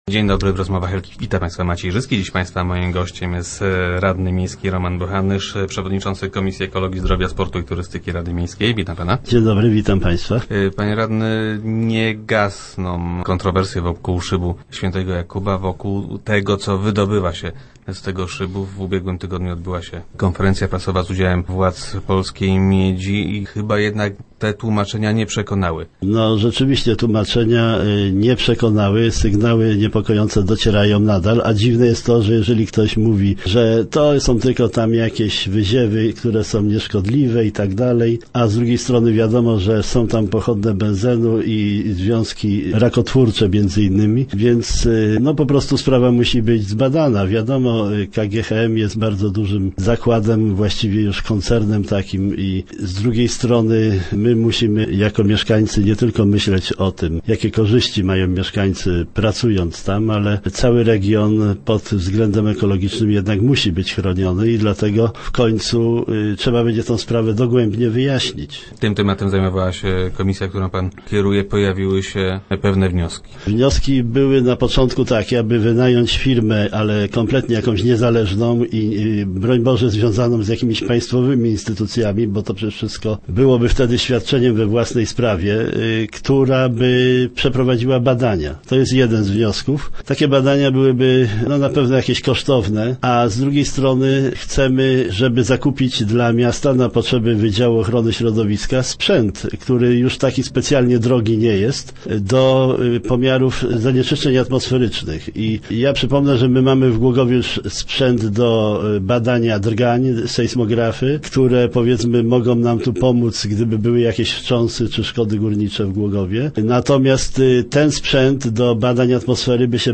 Radni zobowiązali prezydenta Głogowa do działania. Gościem Rozmów Elki był Roman Bochanysz, przewodniczący komisji ekologii i zdrowia.